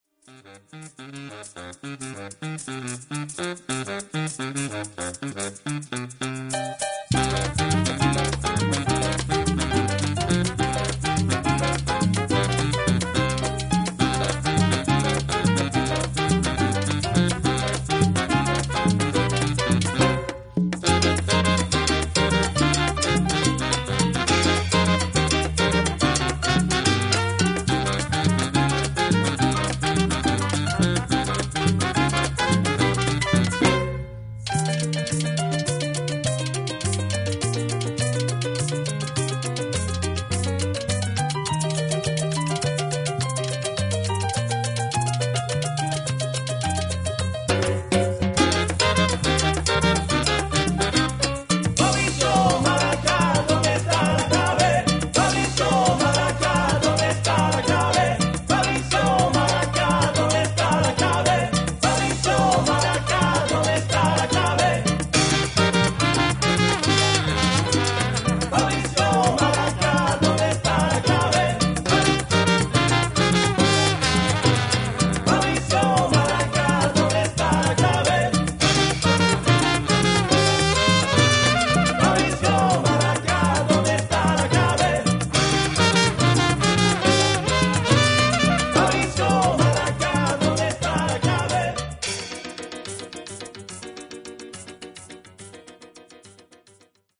Enregistré au Studio Gam à Waimes (Belgique)